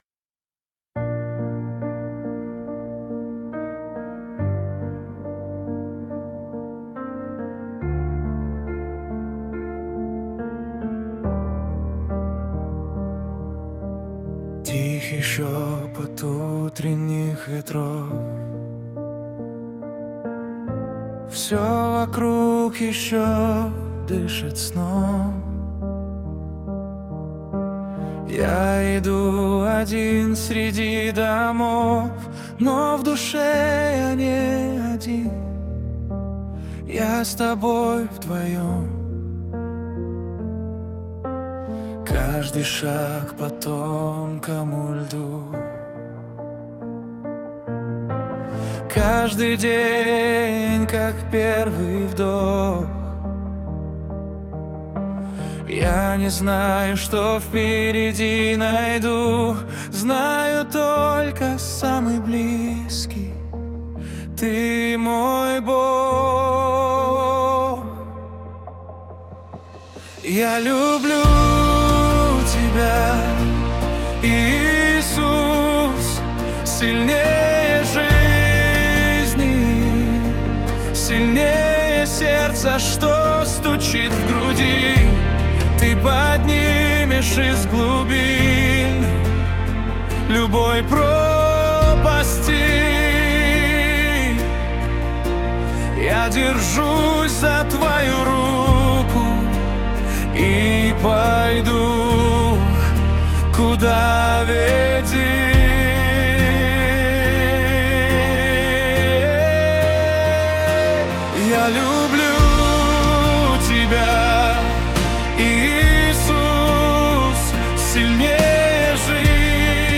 песня ai
101 просмотр 185 прослушиваний 14 скачиваний BPM: 70